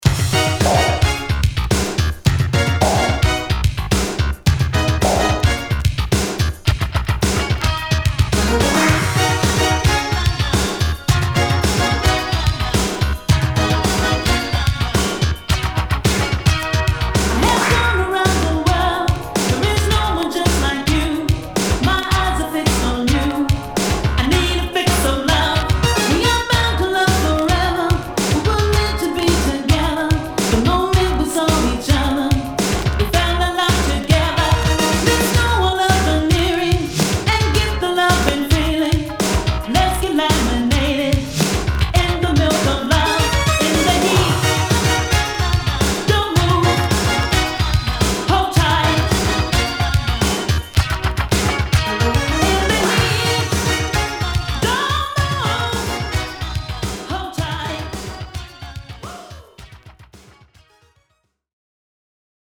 -- Rare Private LA Synth Funk~Boogie!!